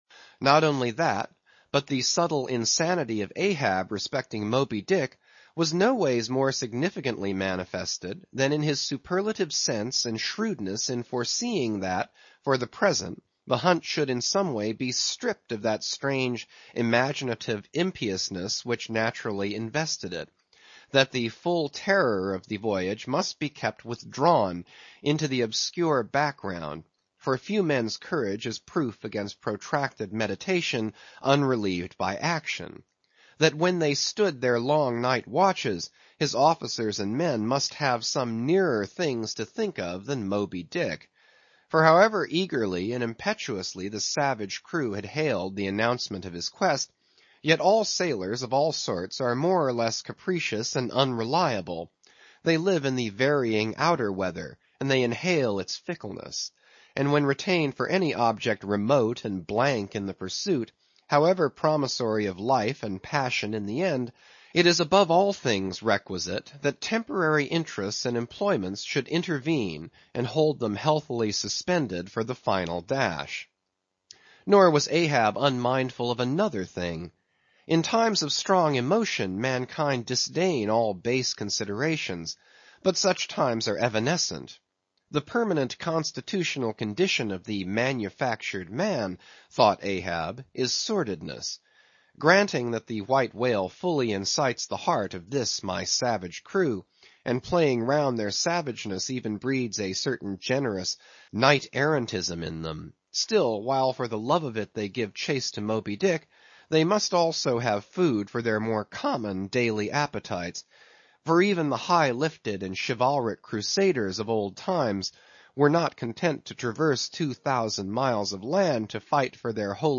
英语听书《白鲸记》第484期 听力文件下载—在线英语听力室